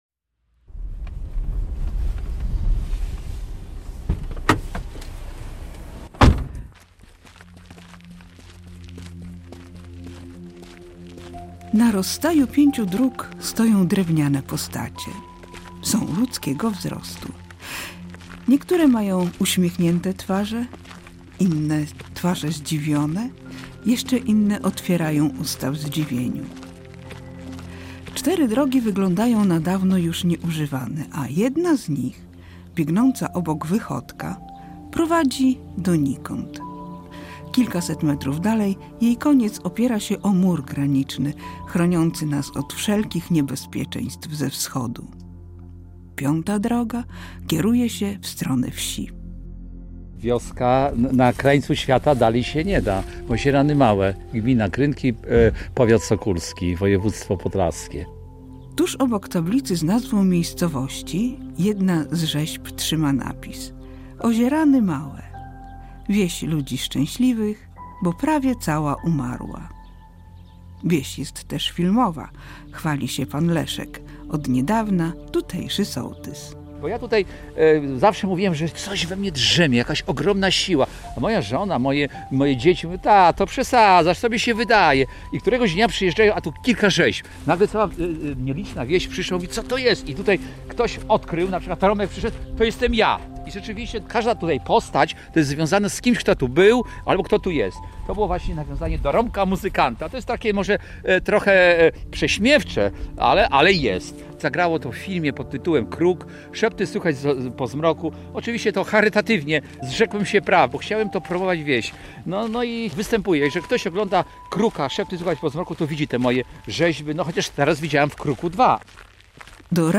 Reportaż | "Wieś ludzi szczęśliwych"